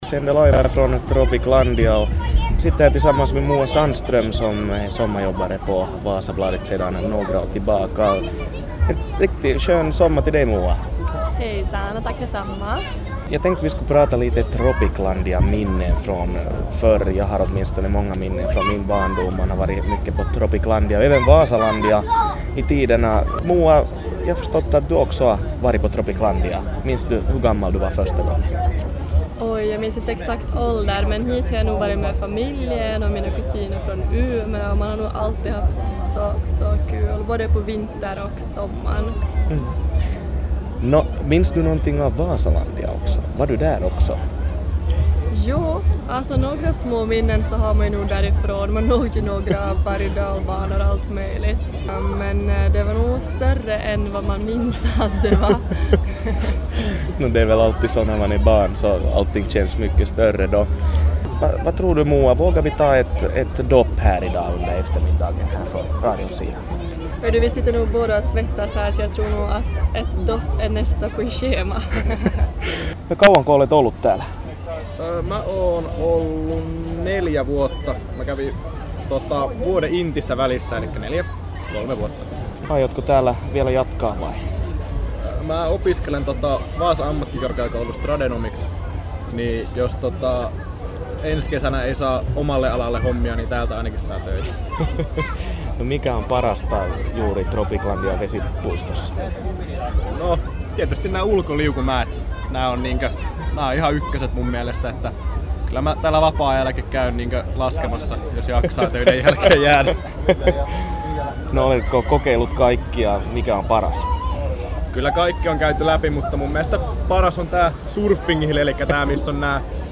Radio Vaasa teki lähetystä Tropiclandian vesipuistosta.